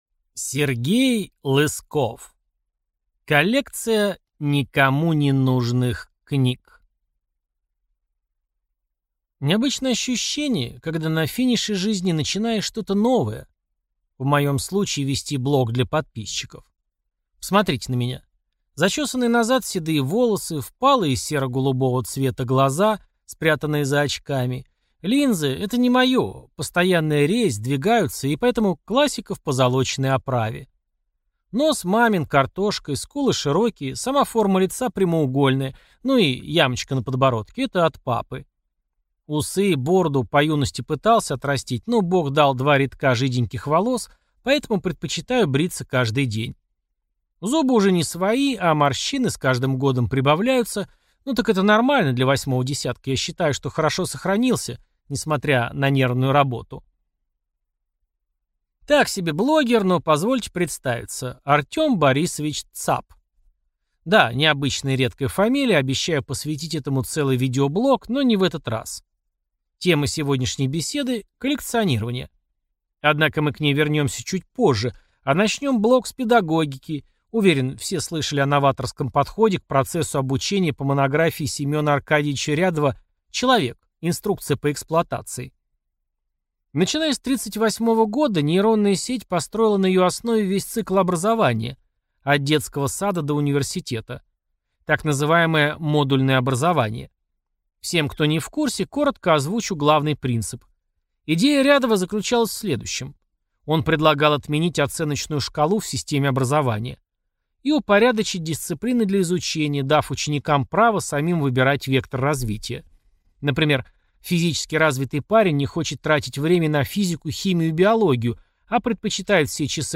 Аудиокнига Коллекция никому ненужных книг | Библиотека аудиокниг